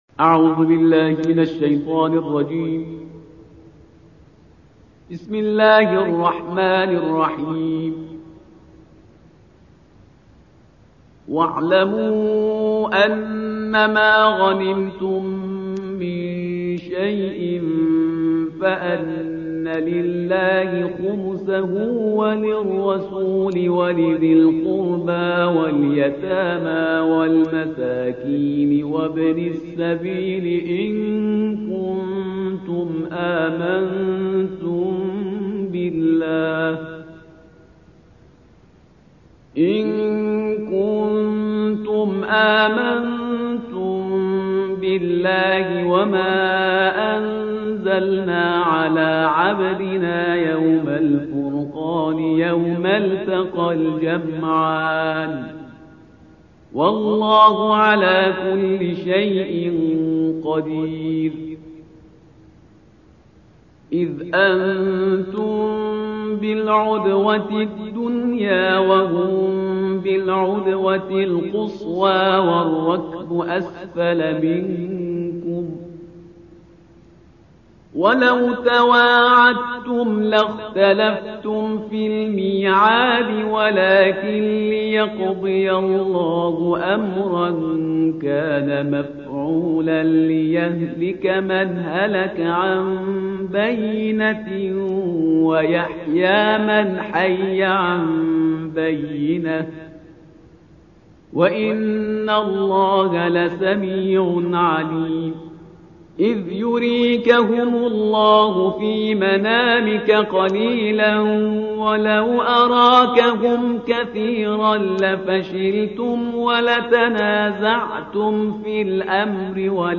تلاوت ترتیل جزء دهم کلام وحی با صدای استاد